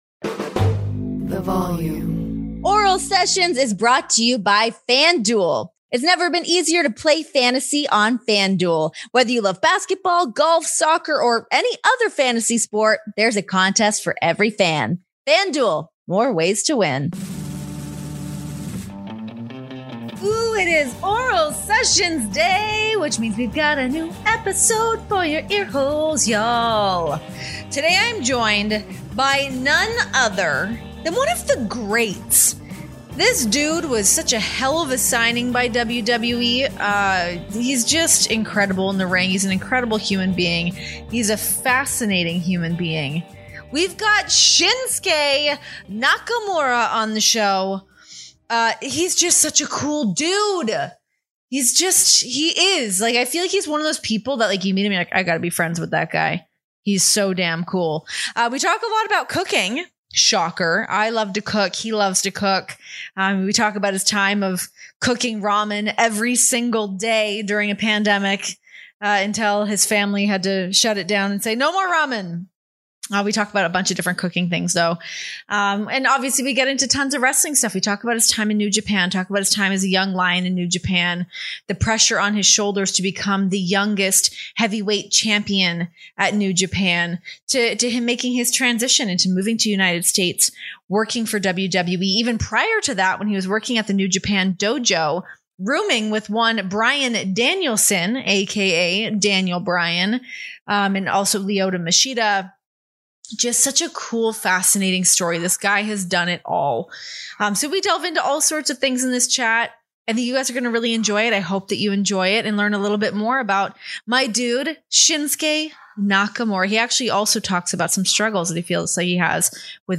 He's an artist in the ring, an artist in the kitchen, and Shinsuke Nakamura is an artist on Oral Sessions as he reunites with his old foil for a rare podcast interview! The former WWE Intercontinental Champion discusses whether he'd open up a restaurant, his checkered past with Brock Lesnar and his love for sun and surf.